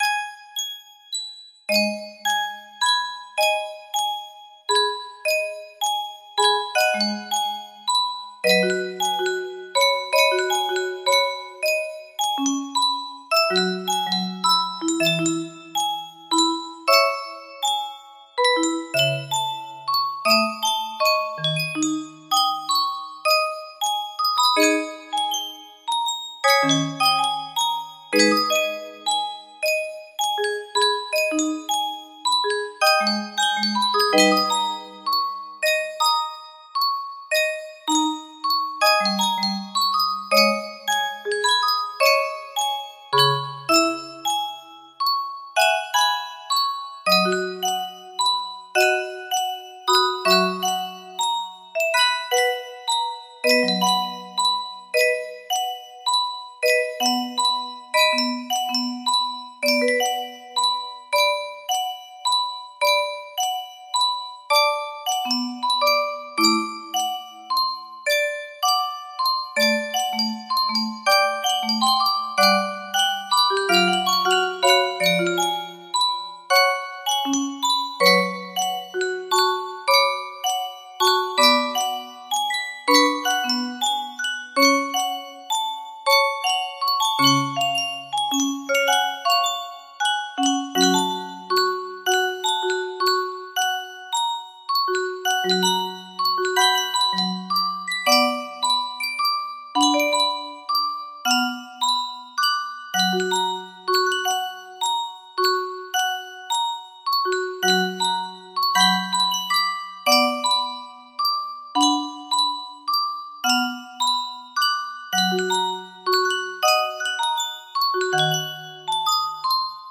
Sonata Moonlight 2025 music box melody
Full range 60